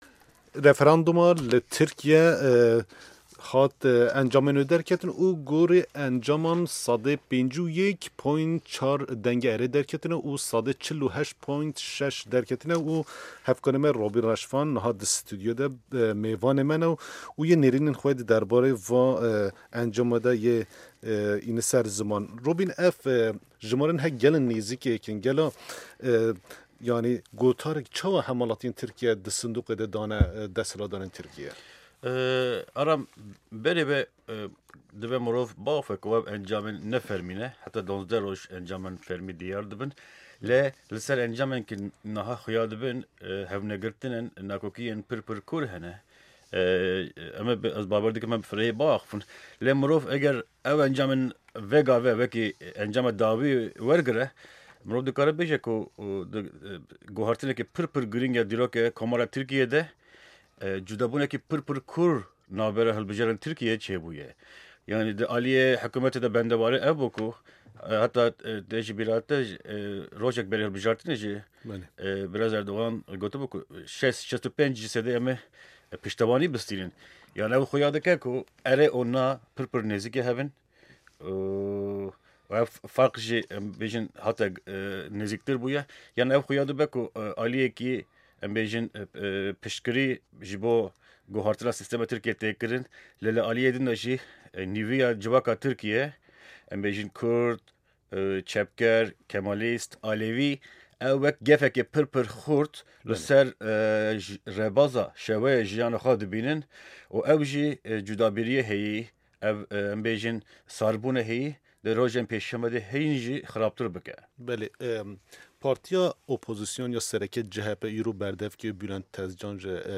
Hevpeyvîn